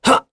Lusikiel-Vox_Jump.wav